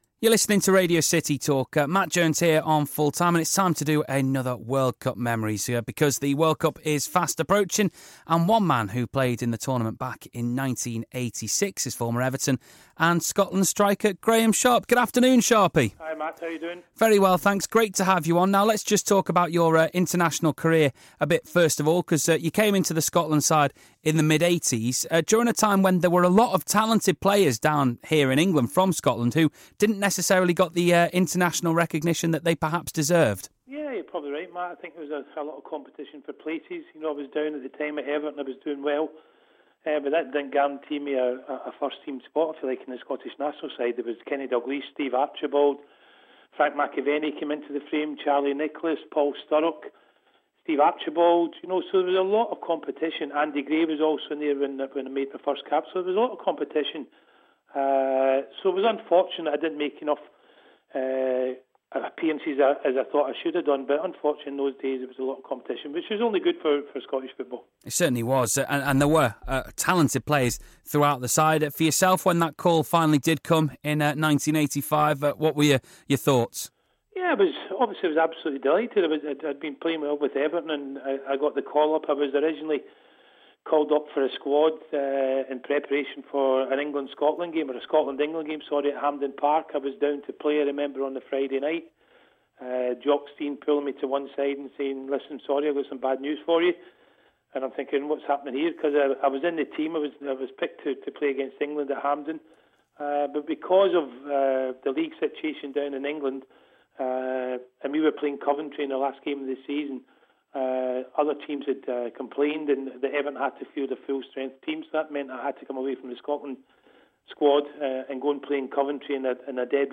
talks with Blues Legend Graeme Sharp about what it was like to be part of the Scotland Squad in 1986